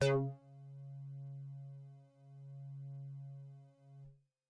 描述：通过Modular Sample从模拟合成器采样的单音。
Tag: CSharp5 MIDI - 注意-73 DSI-利 合成器 单票据 多重采样